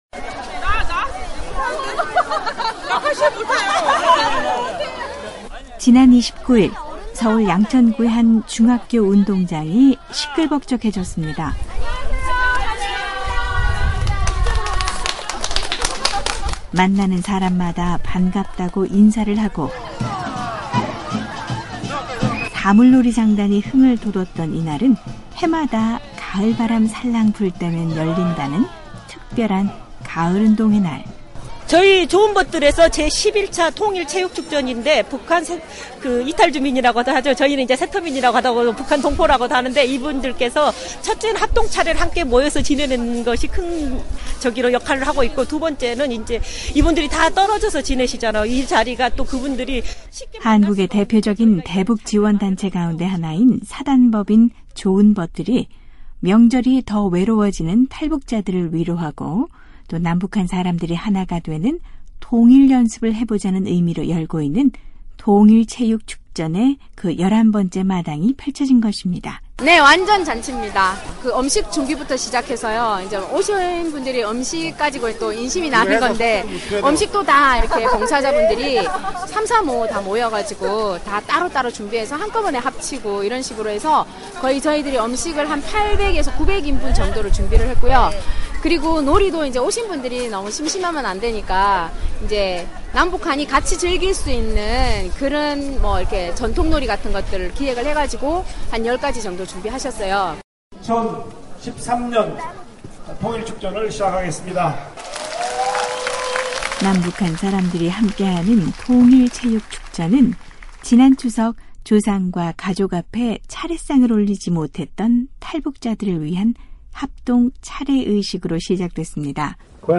음식도 풍성하고, 놀이도 풍성했던 한국식 ‘가을운동회’ 현장으로 안내하겠습니다.